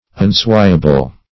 Unswayable \Un*sway"a*ble\, a. Not capable of being swayed.